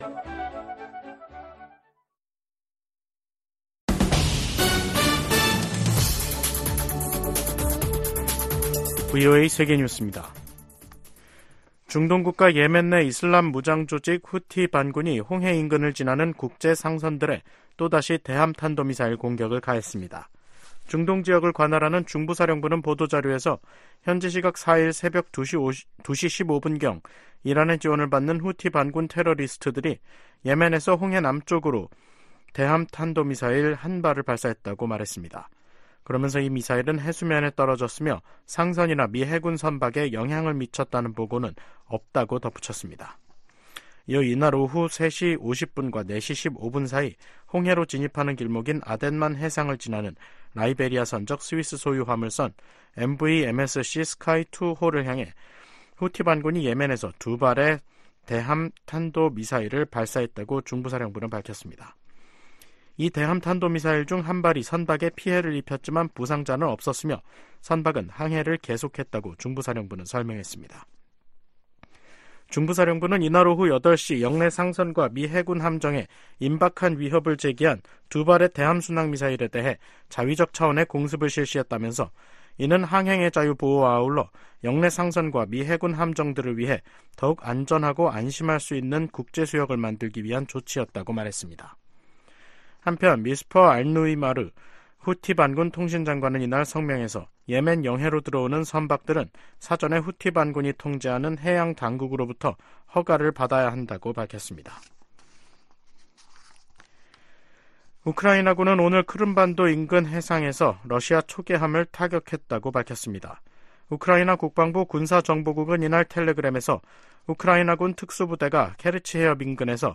VOA 한국어 간판 뉴스 프로그램 '뉴스 투데이', 2024년 3월 5일 2부 방송입니다. 북한의 영변 경수로 가동 움직임이 계속 포착되고 있다고 국제원자력기구(IAEA)가 밝혔습니다. 북한에서 철수했던 유럽 국가들의 평양 공관 재가동 움직임에 미국 정부가 환영의 뜻을 밝혔습니다. 북한은 4일 시작된 미한 연합훈련 '프리덤실드(FS)'가 전쟁연습이라고 주장하며 응분의 대가를 치를 것이라고 위협했습니다.